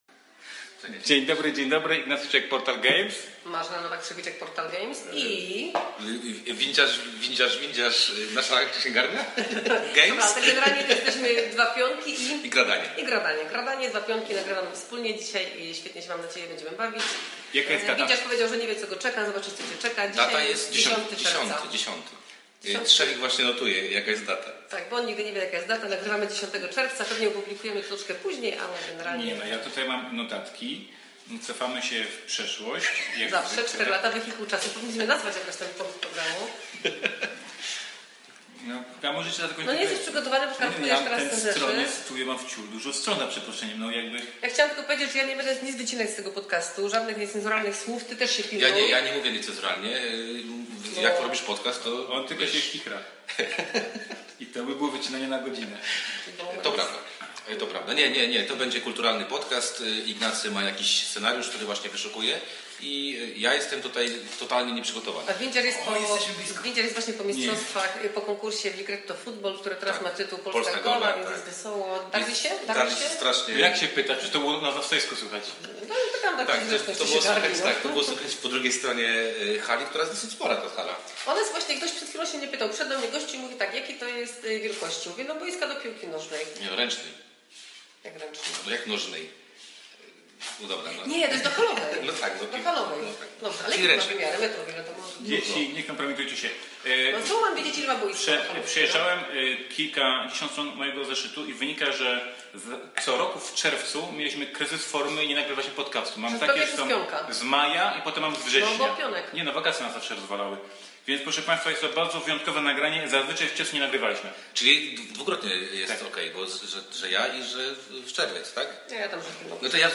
141 odcinek, nagrany na żywo na Pionku.